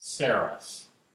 The saros (/ˈsɛərɒs/